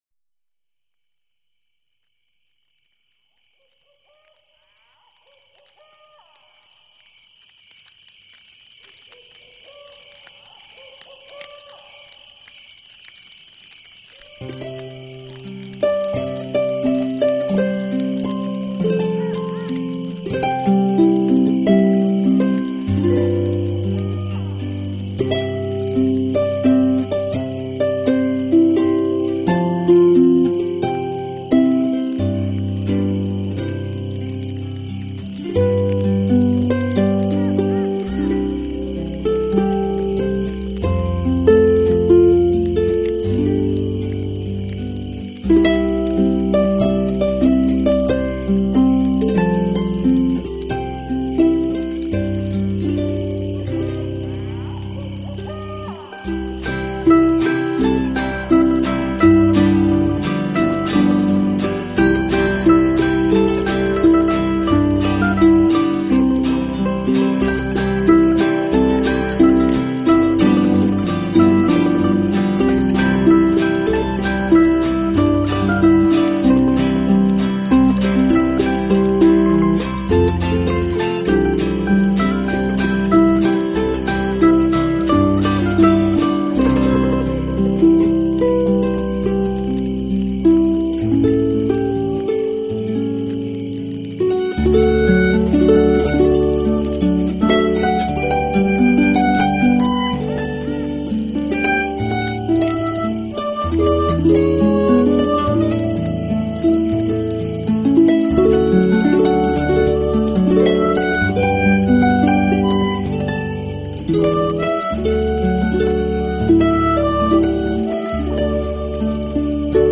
音乐流派：纯乡村竖琴音乐
回味无穷的美国民谣，陶醉不已的柔美竖琴。
让我们聆听竖琴优美的声音，沉浸在优雅的琴声中吧!